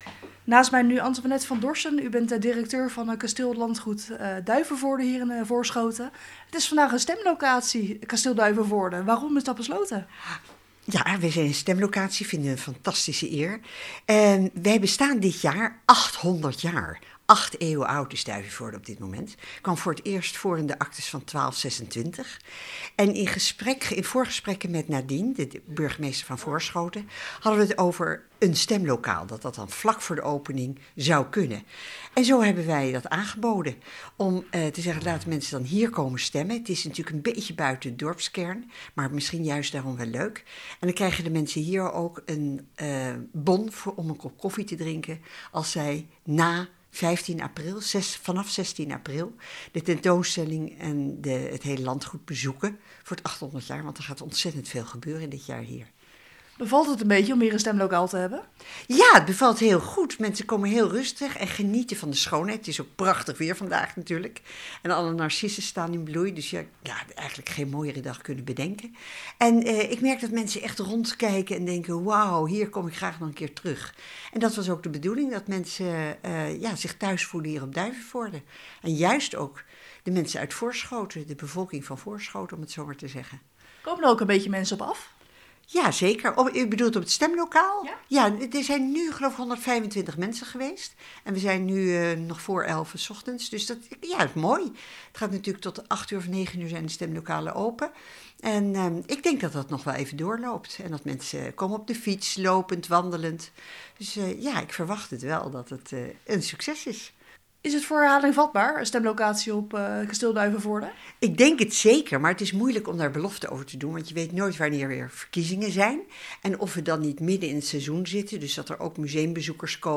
Verslaggever